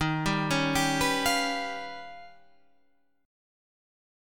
D#+9 Chord
Listen to D#+9 strummed